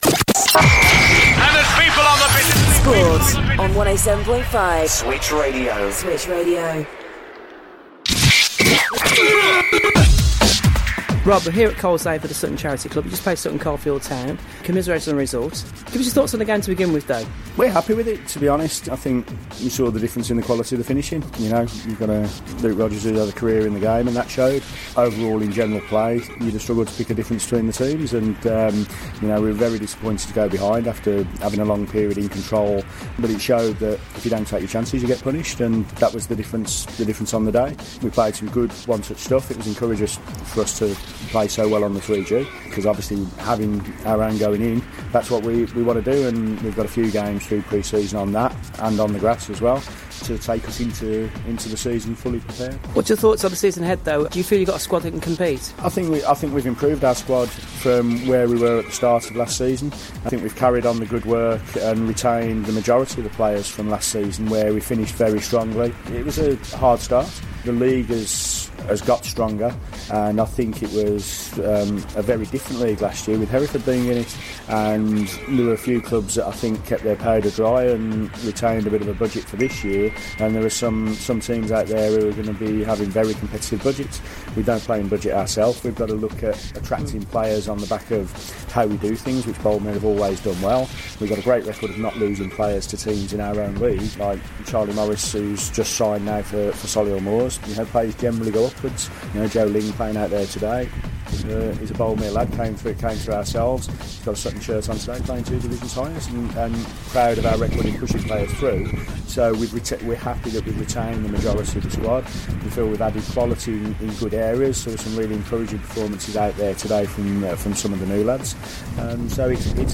Sutton Charity Cup 9 July 2016